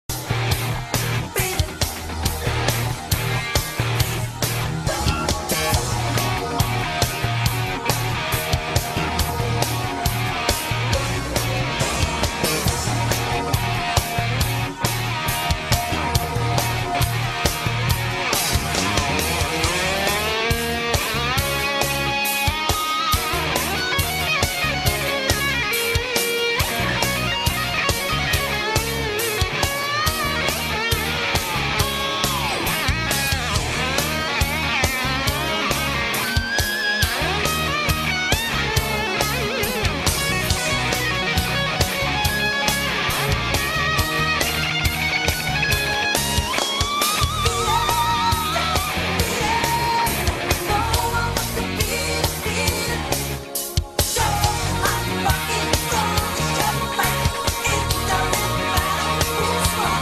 iconic guitar solo